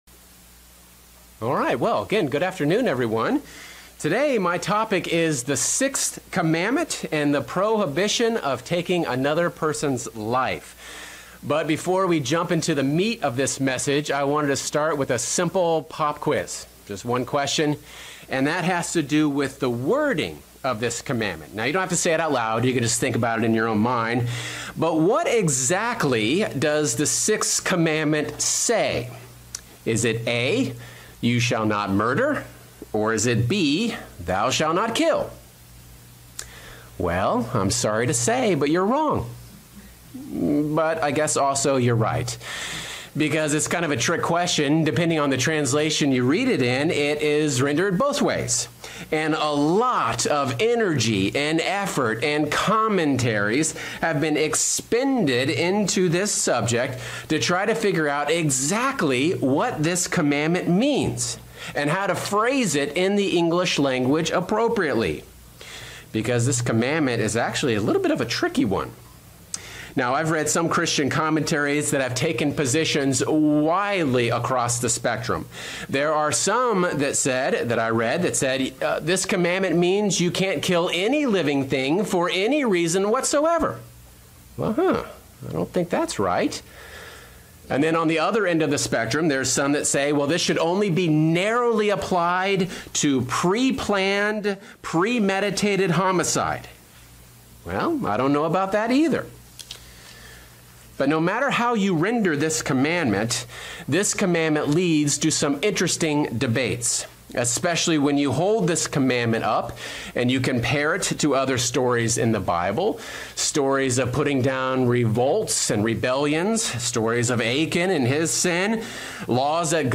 Sermons
Given in Northern Virginia